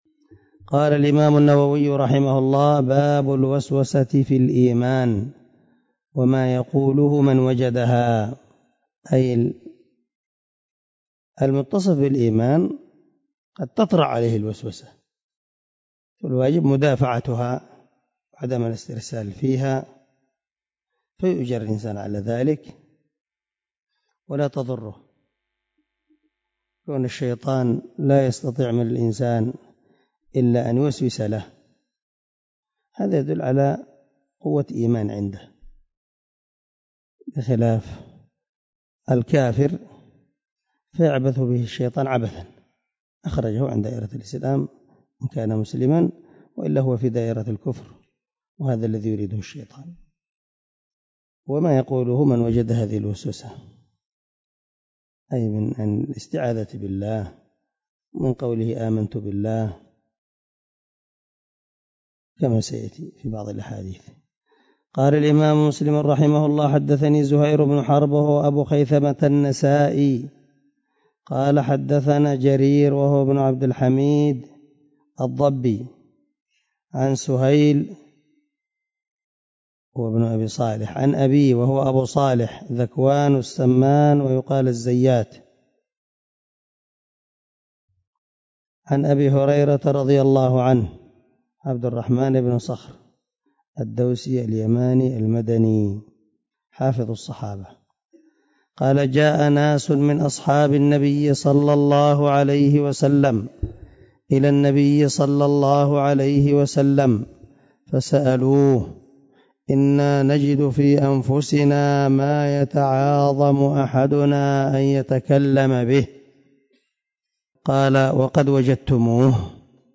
097الدرس 96 من شرح كتاب الإيمان حديث رقم ( 132 - 133 ) من صحيح مسلم